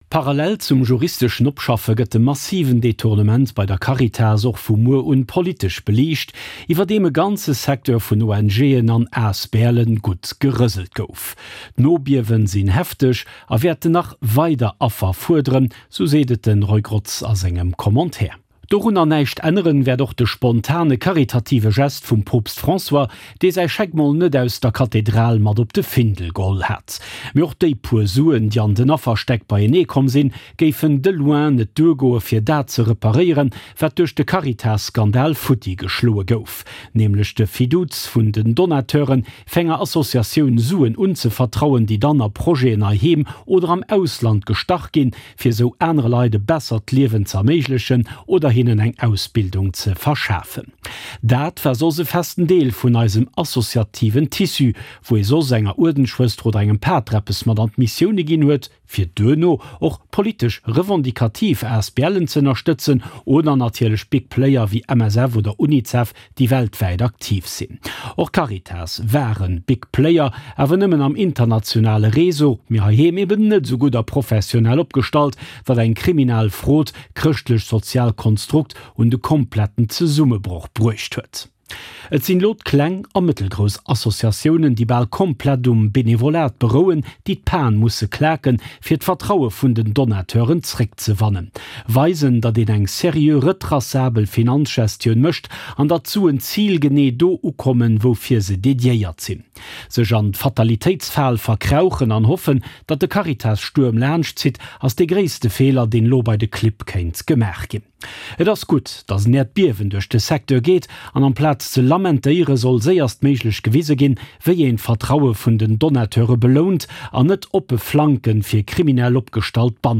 Commentaire Podcast